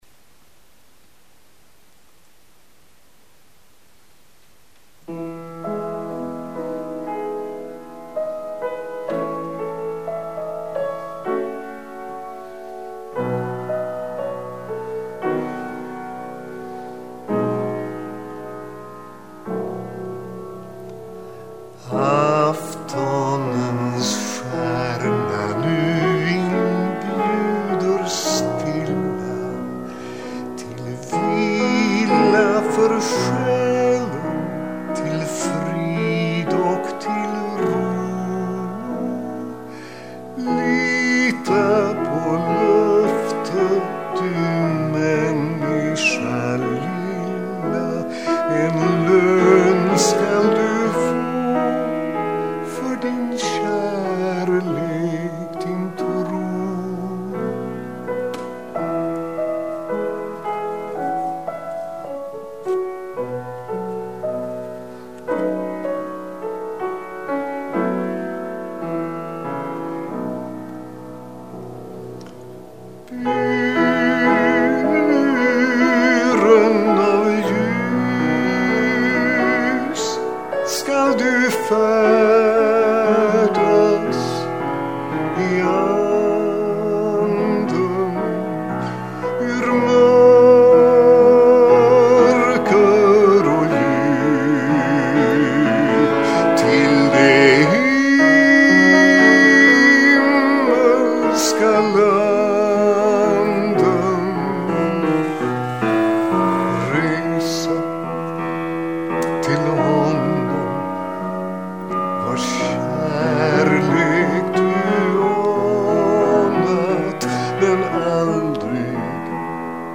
Det rör sig om inspelningar med sång till eget pianoackompanjemang.
Jag ber den som lyssnar notera att alla visorna är tagna ”live”.